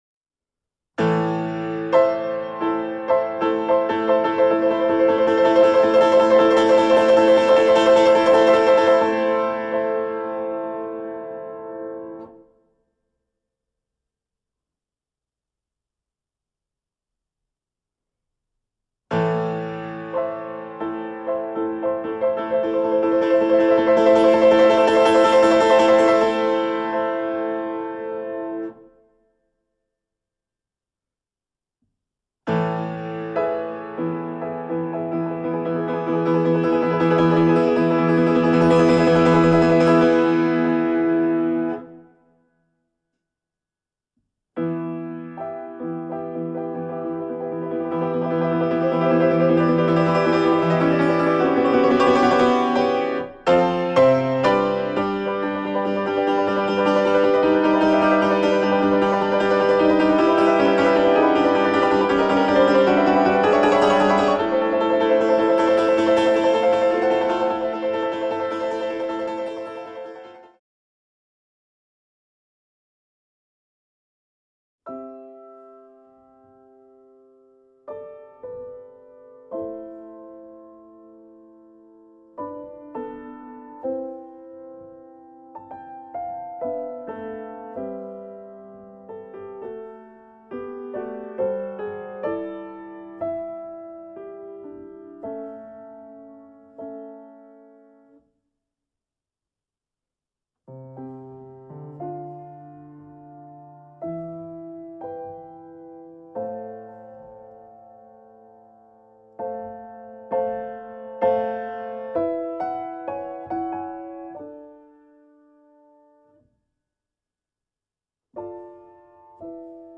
per piano solo